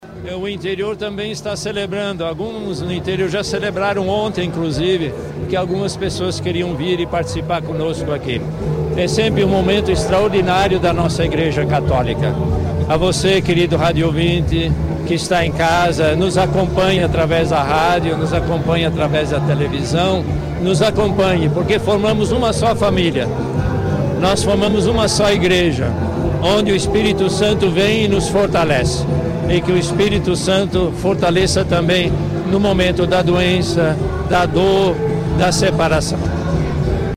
E pouco antes do início da Santa Missa, o arcebispo metropolitano de Manaus, Cardeal Steiner deixou um recado para a população do interior e para o enfermos.